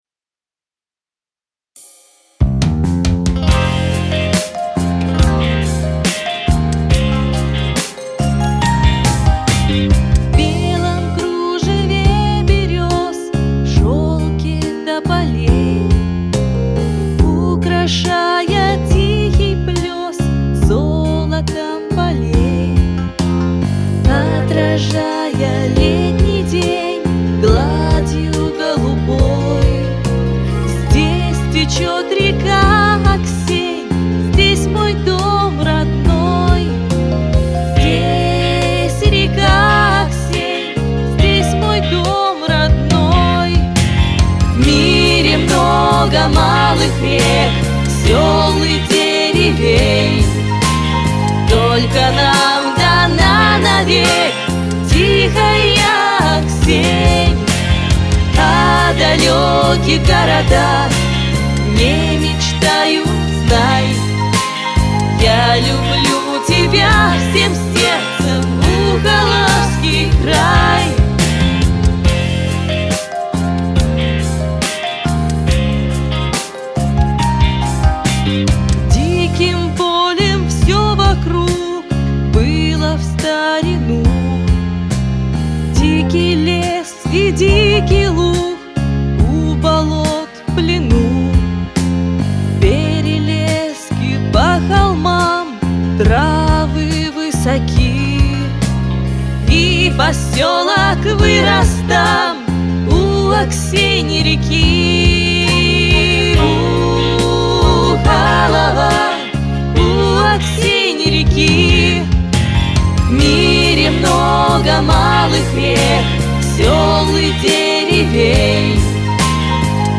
Композиции исполнили молодые артисты.